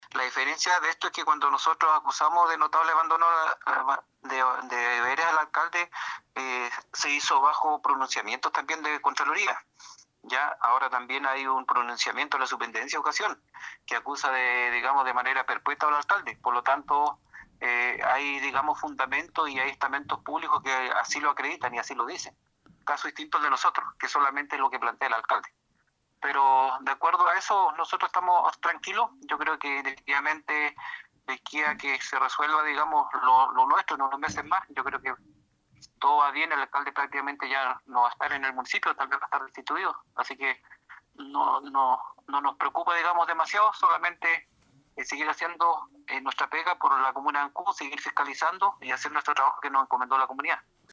11-CONCEJAL-ALEX-MUNOZ-2.mp3